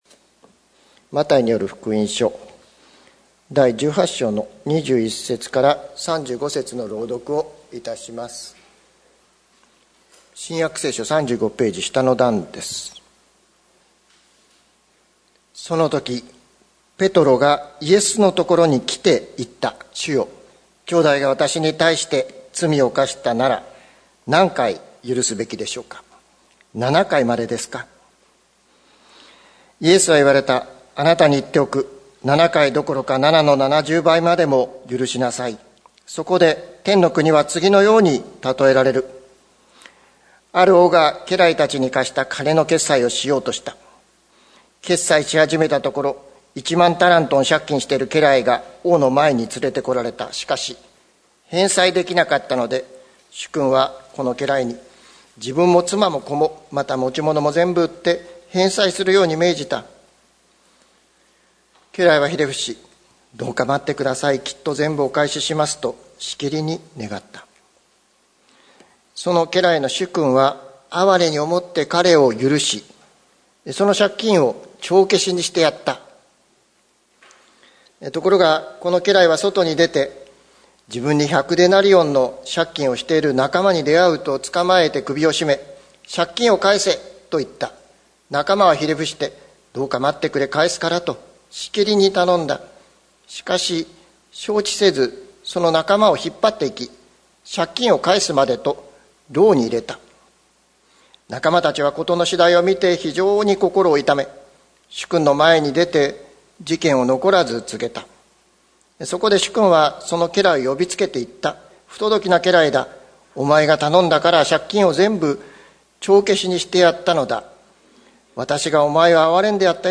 2020年06月21日朝の礼拝「心から赦せますか？」関キリスト教会
説教アーカイブ。
※ ここに記されていますのはあくまでも原稿です。現実になされた説教とは内容が異なるところも多々ございます。